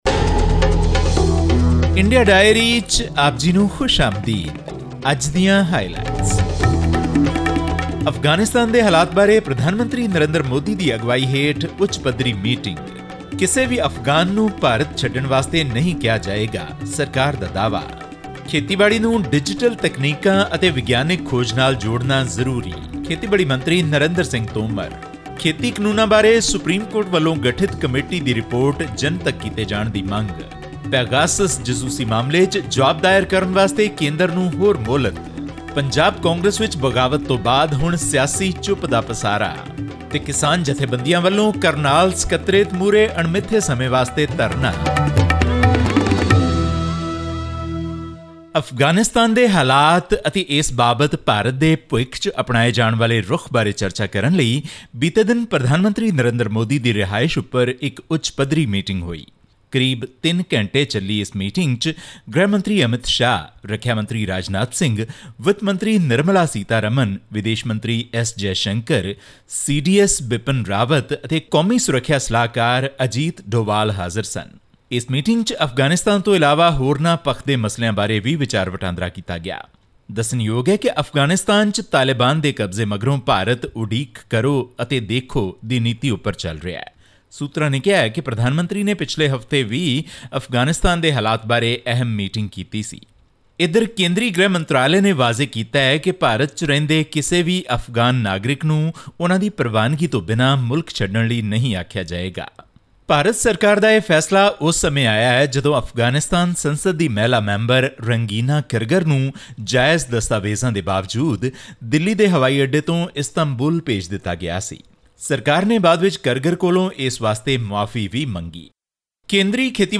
Union Minister for Agriculture and Farmers Welfare, Narendra Singh Tomar has urged the states to take advantage of the Agriculture Infrastructure Fund so that benefits can reach small and marginal farmers who lack basic facilities. All this and more in our weekly news bulletin from India.